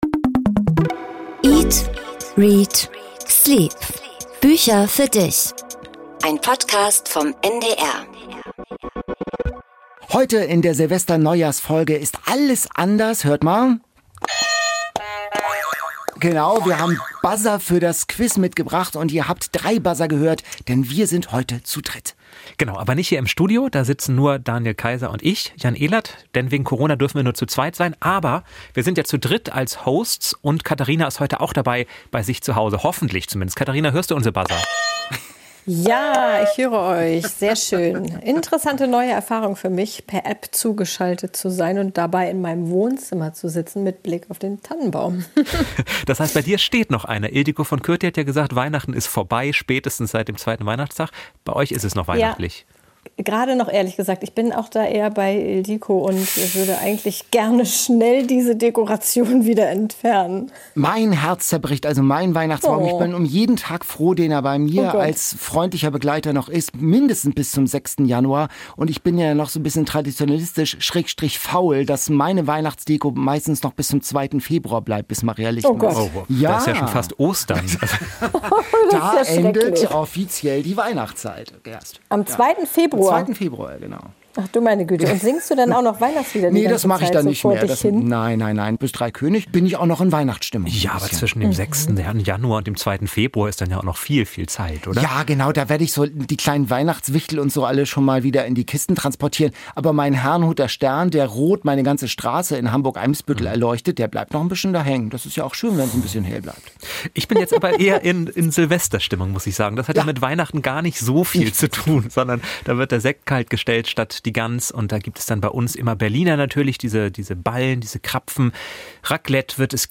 In der letzten Podcast-Folge des Jahres gibt es zum ersten Mal einen Bücher-Talk zu dritt!
Im Quiz wird diesmal um die Wette gebuzzert, was hörbar Spaß macht.